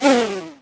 bea_no_ammo_01.ogg